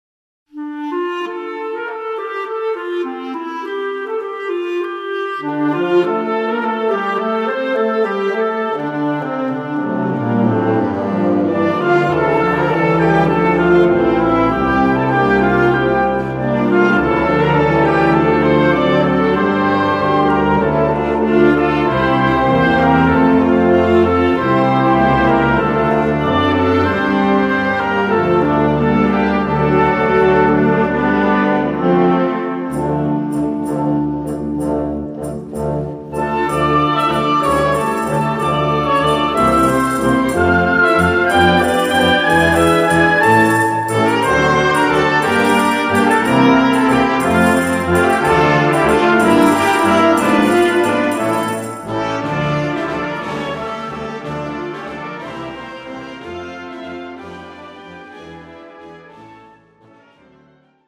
Gattung: Filmmusik
A4 Besetzung: Blasorchester Zu hören auf